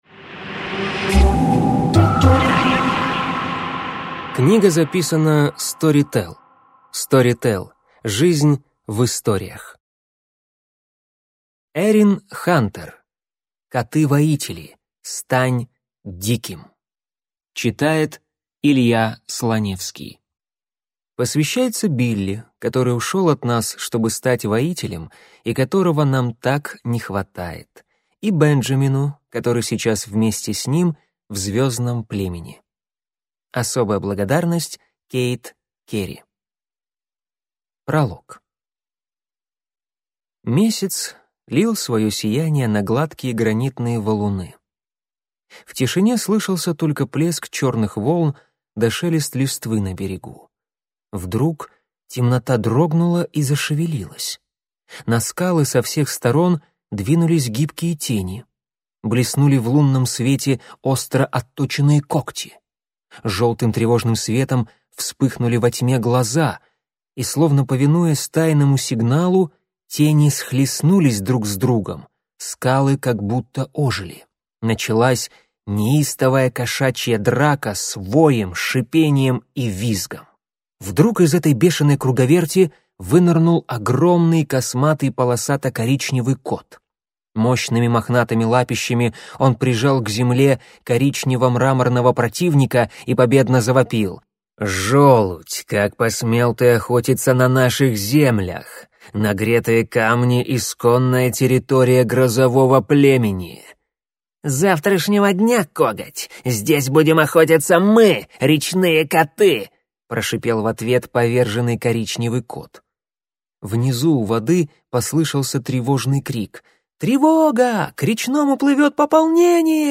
Аудиокнига Стань диким!